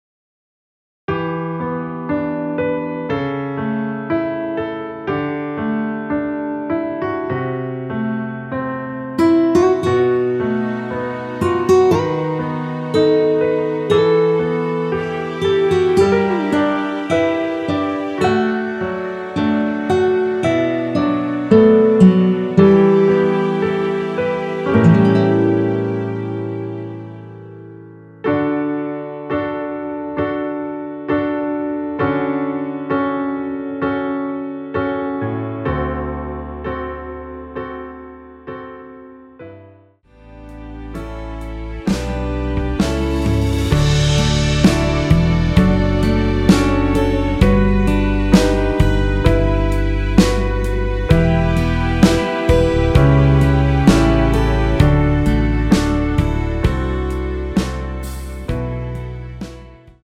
원키에서(+5)올린 MR이며 대부분의 여성분들이 부르실수 있는 키로 제작 하였습니다.
앞부분30초, 뒷부분30초씩 편집해서 올려 드리고 있습니다.